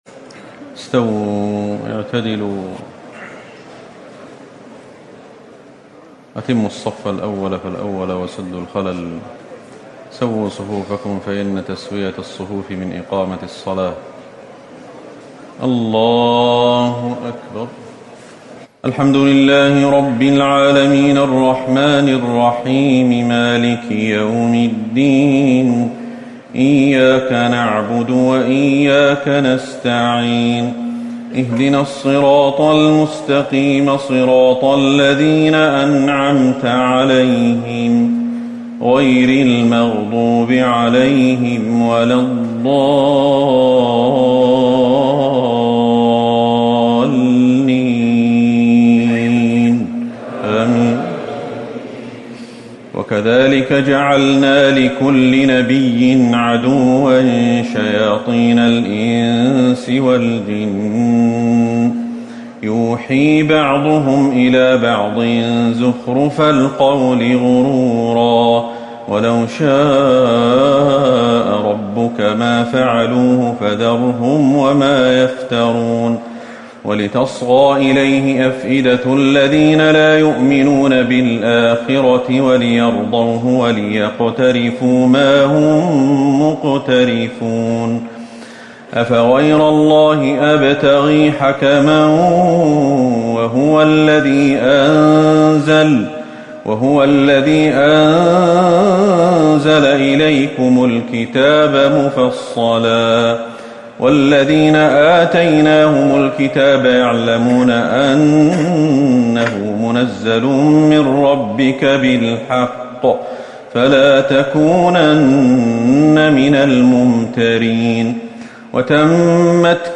تهجد ٢٨ رمضان ١٤٤٠ من سورة الأنعام ١١٢ - الأعراف ٣٠ > رمضان 1440هـ > التراويح